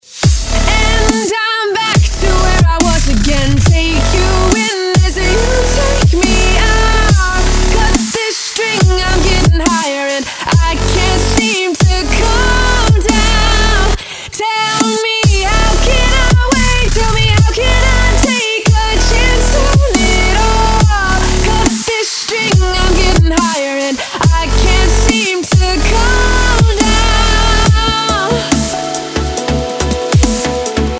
DubStep / Дабстеп